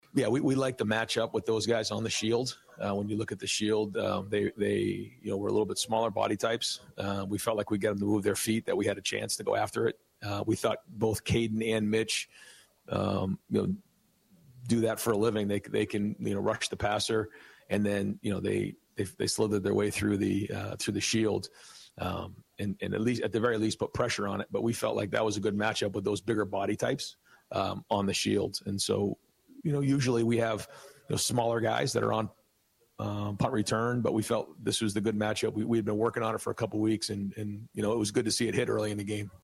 EXCERPTS FROM RYAN DAY’S POSTGAME PRESS CONFERENCE